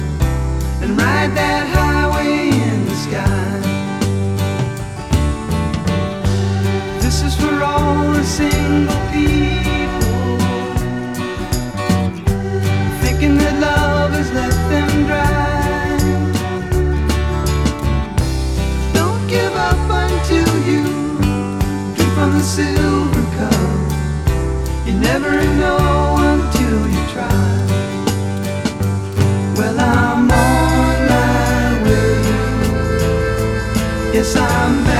Жанр: Поп / Рок / Фолк-рок